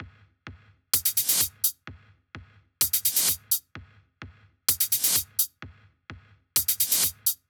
VFH2 128BPM Tron Quarter Kit 9.wav